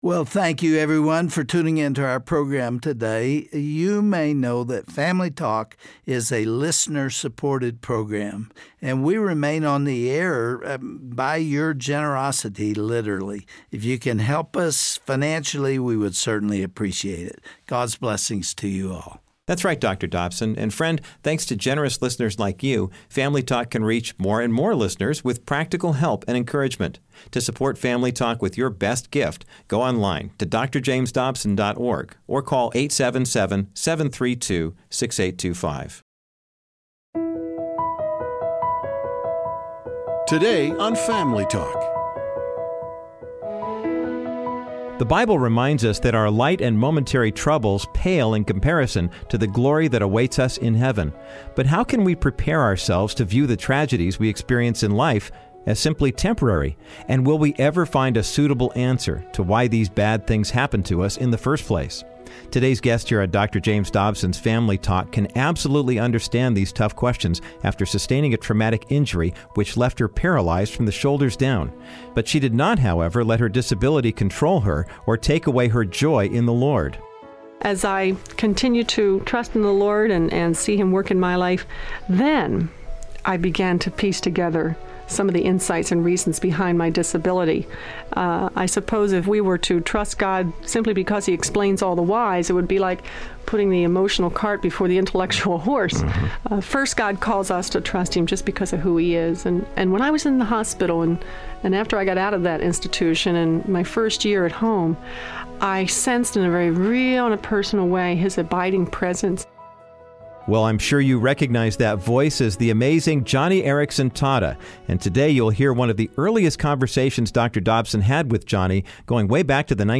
Todays guest on Family Talk is Joni Eareckson Tada, who honestly explores the question of why God allows tragedy. She talks with Dr. Dobson about her horrific diving accident that left her paralyzed from the shoulders down and emboldens listeners to continue in trusting God, even if they can't track His purposes.